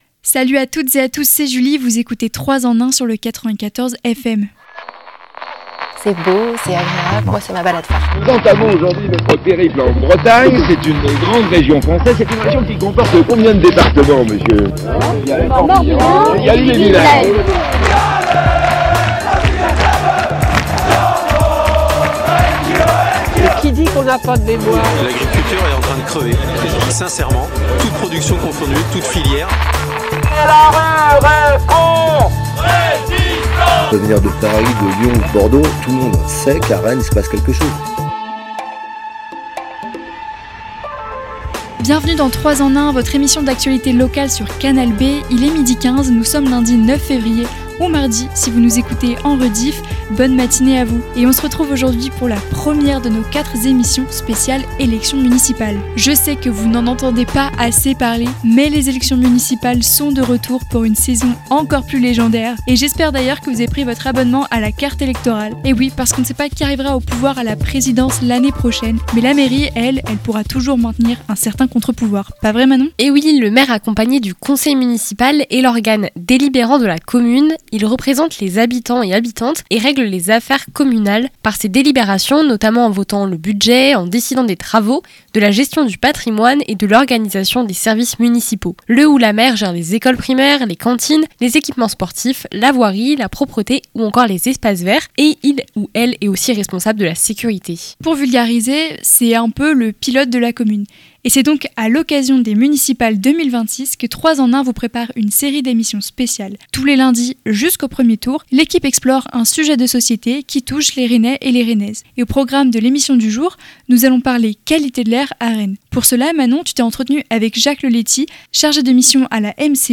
Chaque lundi, jusqu'au 9 mars, nous traitons un sujet de société, à l’échelle de la ville de Rennes. À travers des micro-trottoirs et interventions de spécialistes, l'objectif est de comprendre les enjeux de ces problématiques et du rôle de la mairie dans leurs potentielles évolutions.